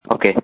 Screams from December 3, 2020
• When you call, we record you making sounds. Hopefully screaming.